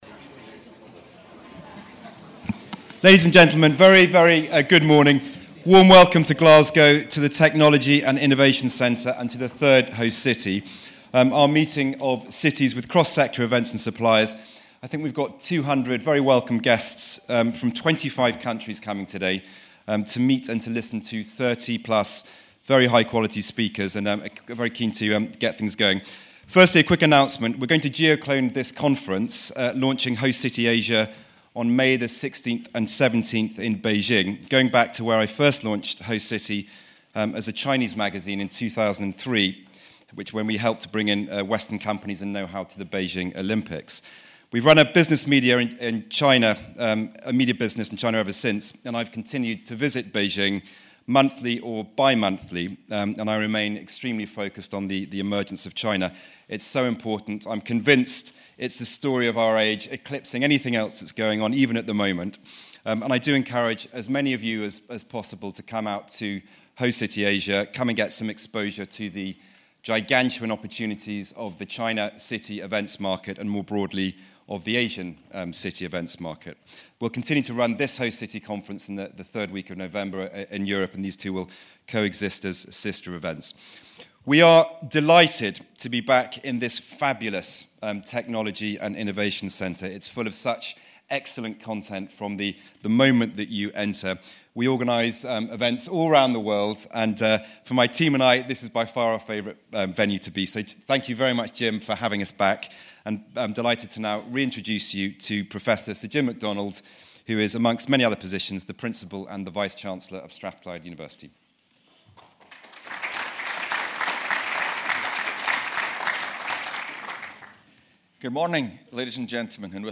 Welcome Remarks & Sir Craig Reedie's Keynote Speech
This is followed by Sir Craig Reedie CBE, President, World Anti-Doping Agency's Keynote Speech